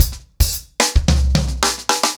TimeToRun-110BPM.3.wav